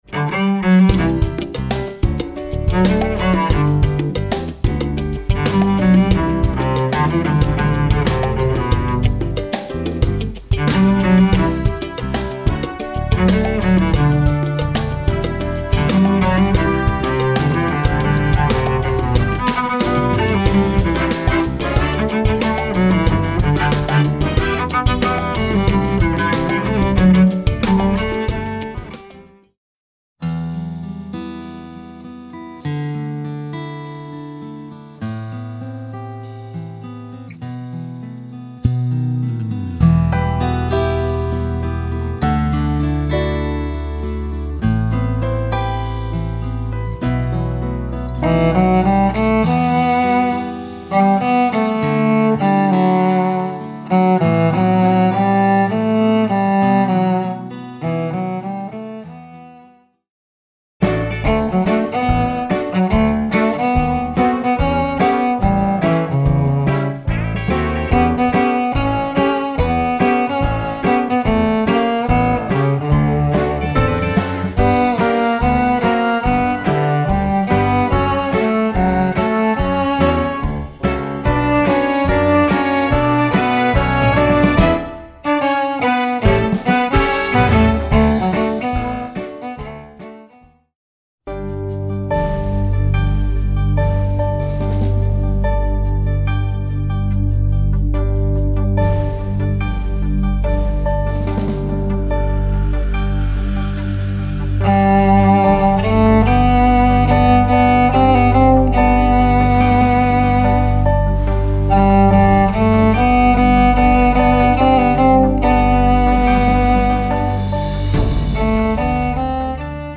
Voicing: Cello and Online Audio